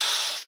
foundry-smoke-puff.ogg